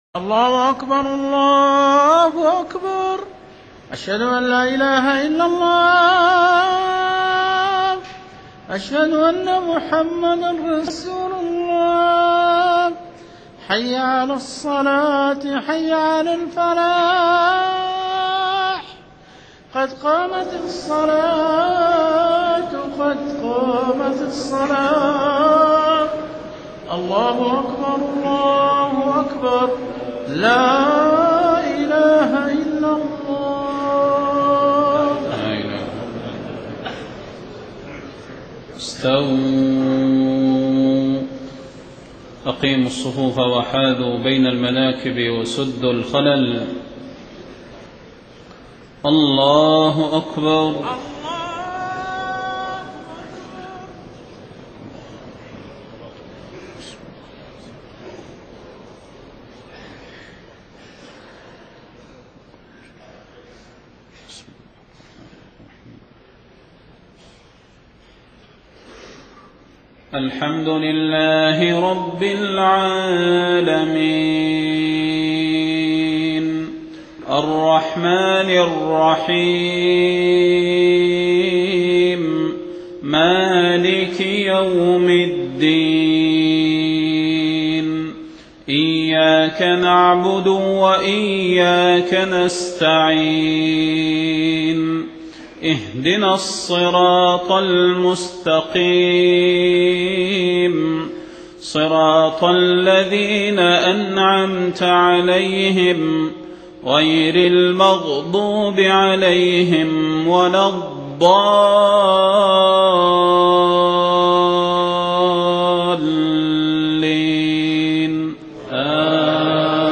صلاة المغرب 30 ذو الحجة 1429هـ سورتي الفلق والناس > 1429 🕌 > الفروض - تلاوات الحرمين